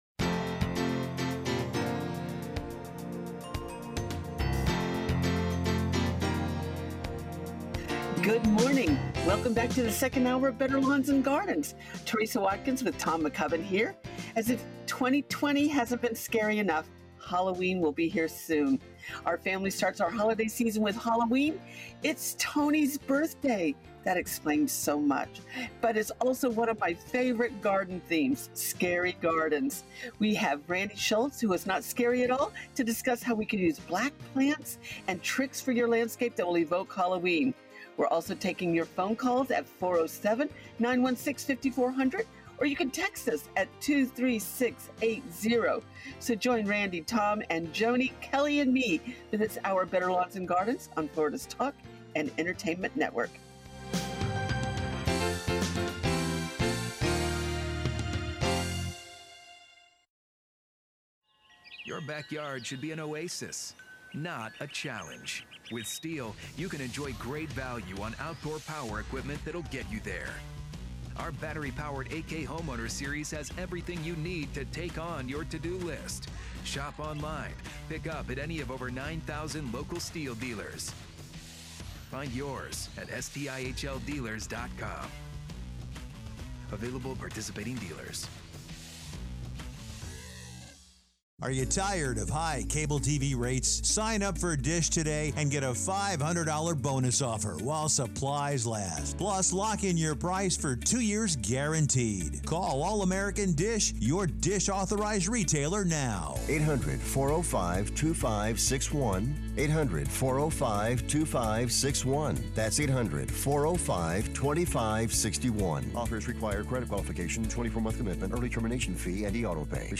Listen to Better Lawns and Gardens every Saturday 7am - 9am EST.